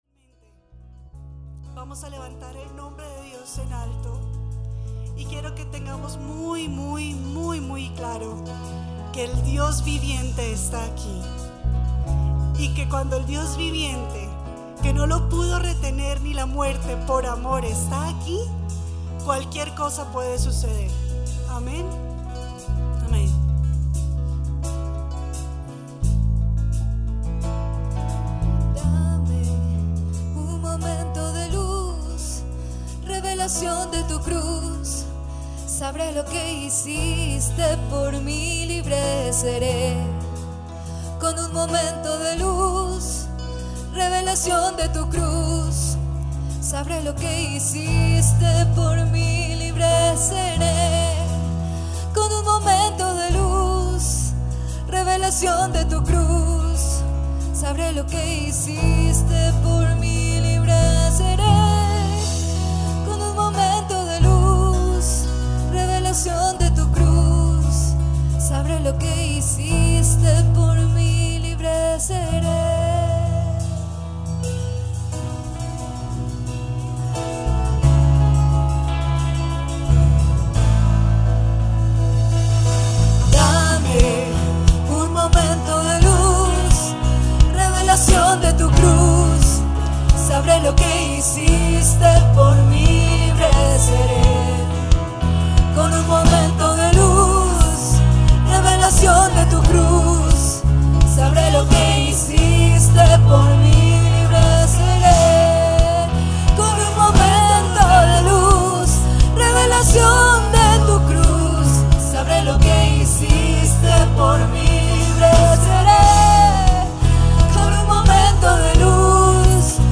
Alabanza marzo 27.mp3